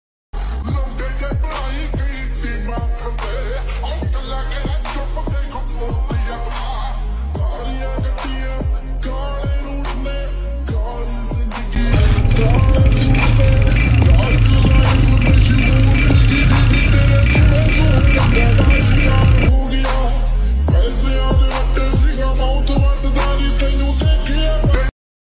Sankai Salancer Available stoke 💯 sound effects free download
Sankai Salancer Available stoke 💯 granted sound CG125 lovers